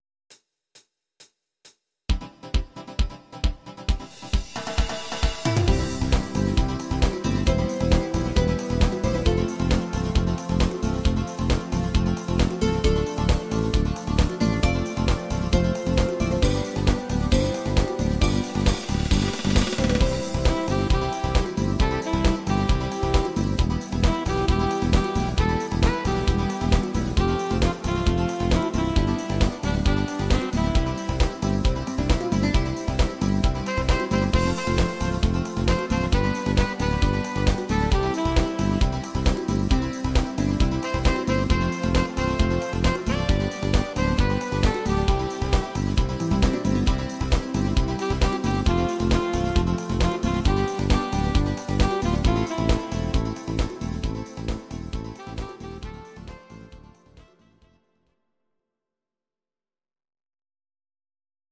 Audio Recordings based on Midi-files
Pop, German, 2000s